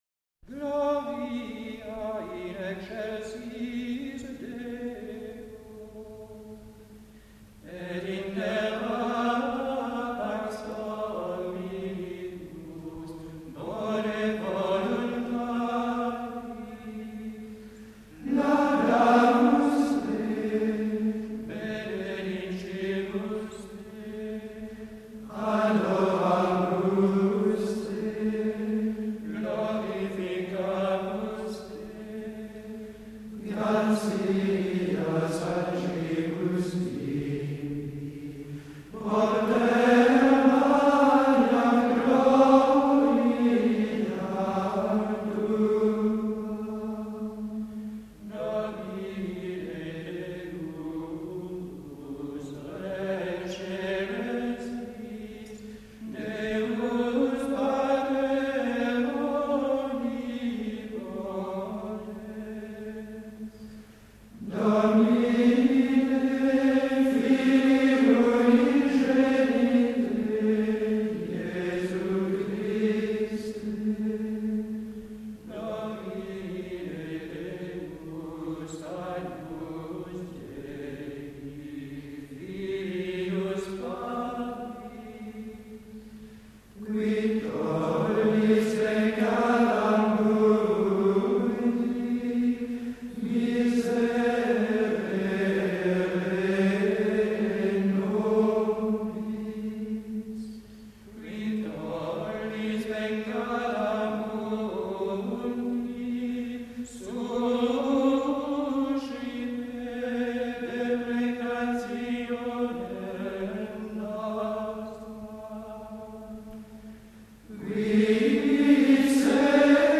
C’est le deuxième Glória marial du répertoire grégorien.
Nous sommes en présence d’un 8e mode, donc aussi un mode de Sol, mais beaucoup plus sobre que son voisin festif. Il va ainsi très bien pour commémorer plus discrètement Marie, ou chanter le Seigneur lors des petites fêtes mariales qui s’accommodent de cette mélodie simple, moins accidentée, moins neumatique également.
L’intonation forme une belle courbe qui part du Do aigu, dominante du 8e mode, descend jusqu’au Mi, puis remonte jusqu’au Si avant de se poser sur la tonique de ce même mode, le Sol. D’emblée, la grâce et la fermeté s’allient très naturellement.
Et in terra reprend le motif mélodique de in excélsis, puis la mélodie plonge de façon très belle vers le Ré grave, sur homínibus, avant de remonter se fixer sur une cadence en Sol.
Pour la première fois, la mélodie va monter jusqu’au Ré aigu, sur les mots qui sedes ad déxteram Patris.
Le Amen final s’enroule autour du Sol.